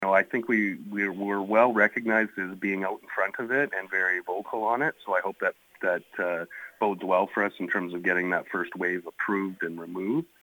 Mayor Aaron Stone says they should find out any day now whether or not that funding has been approved…..